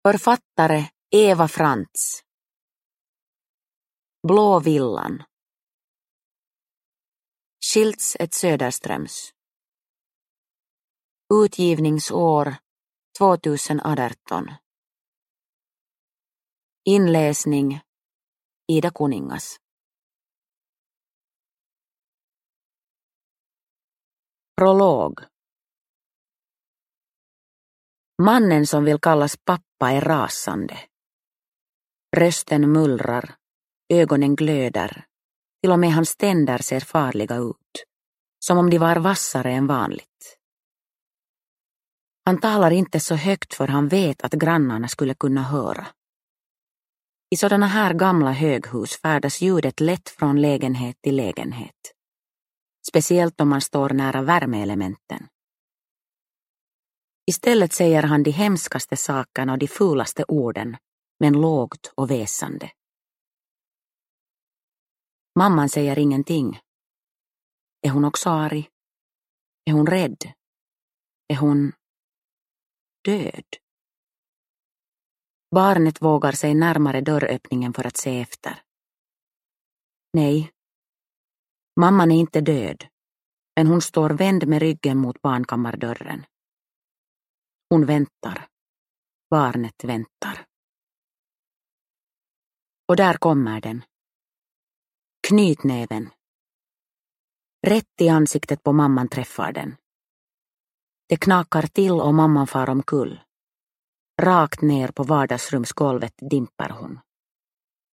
Blå villan – Ljudbok – Laddas ner